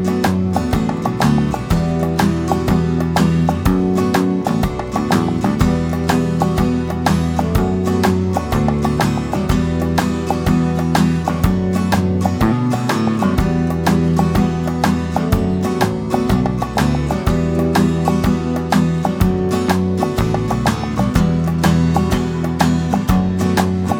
no Backing Vocals Pop (1970s) 4:07 Buy £1.50